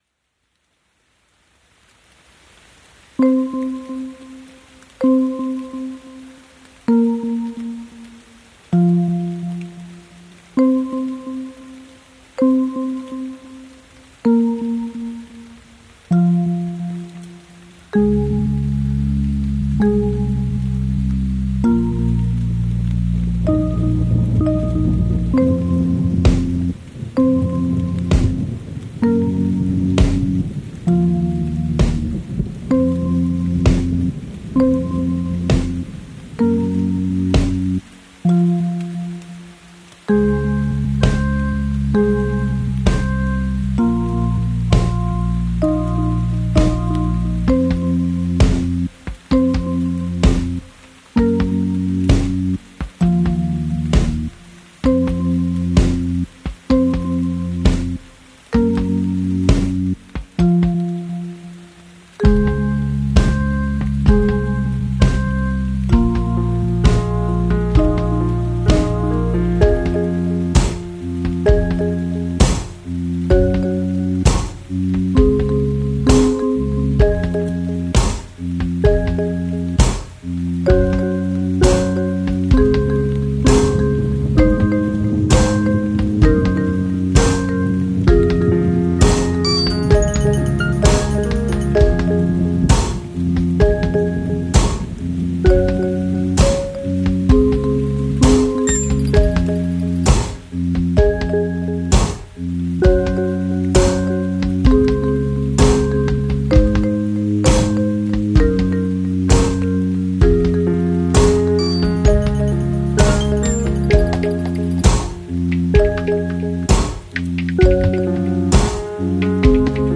Voices and instrumental sound samples
Recorded in 2015